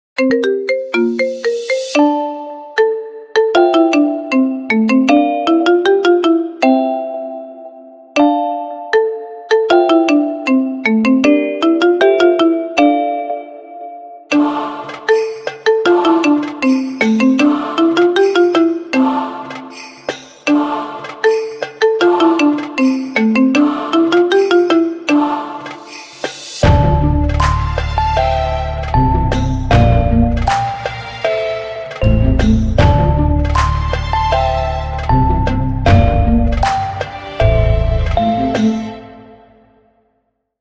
Categoría Marimba Remix